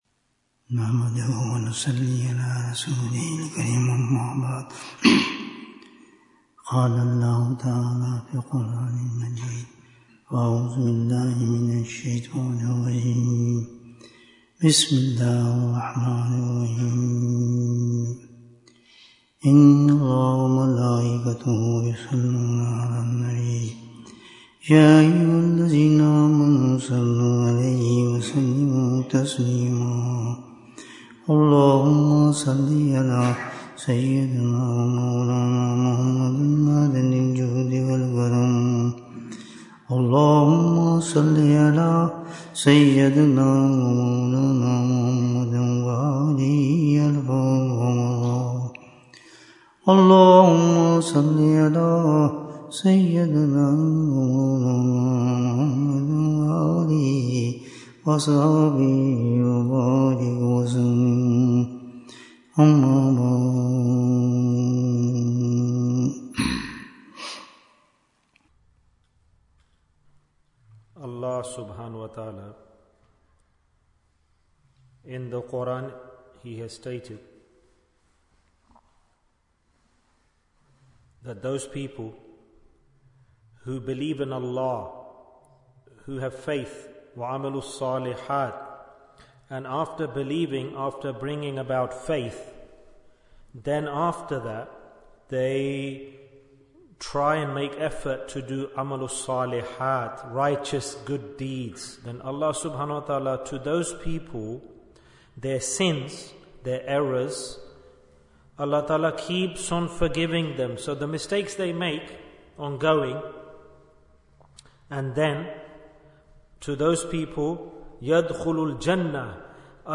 Naqshbandi Terminologies Bayan, 69 minutes24th October, 2024